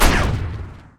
poly_shoot_nuke.wav